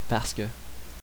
I was born and raised in Quebec and still reside there.